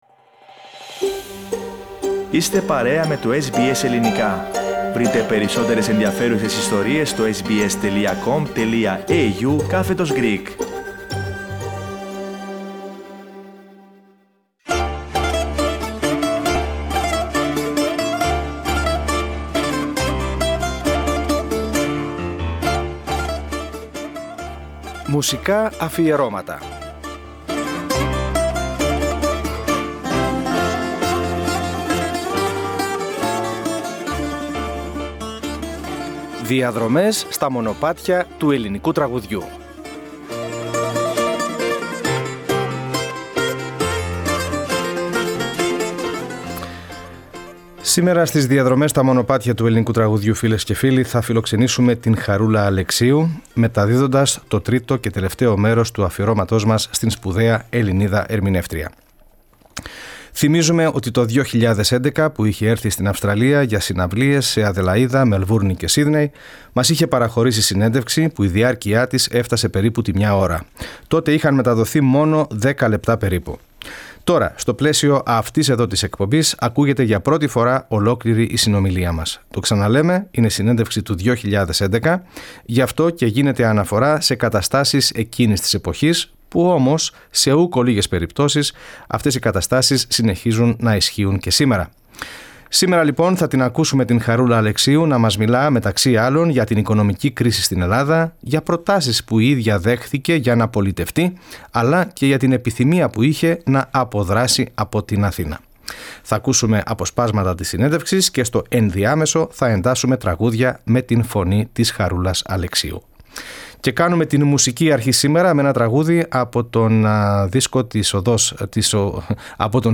Στο τρίτο μέρος μιας σπάνιας αρχειακής συνέντευξης, η αγαπημένη καλλιτέχνιδα Ελλήνων ανά την υφήλιο Χάρις Αλεξίου δεν αφήνει τίποτα ασχολίαστο: από την οικονομική κρίση και προτάσεις που είχε δεχτεί να πολιτευτεί, μέχρι βέβαια... το λαϊκό τραγούδι.